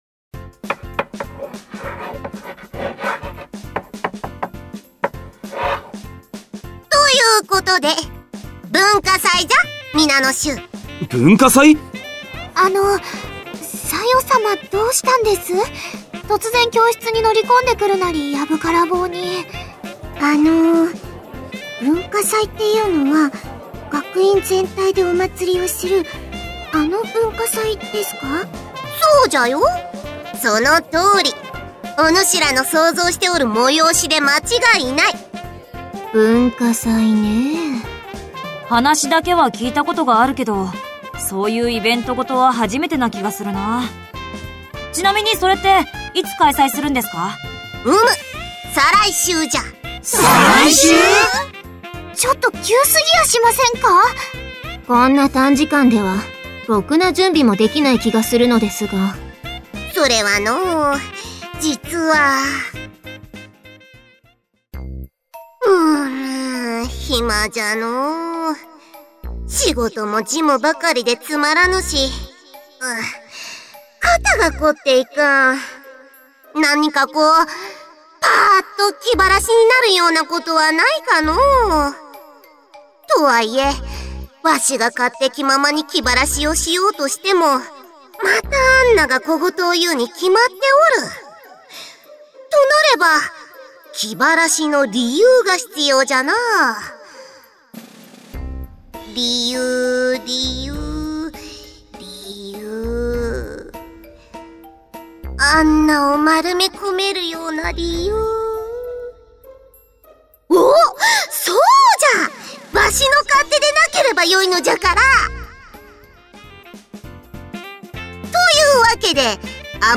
dt_dramacd.mp3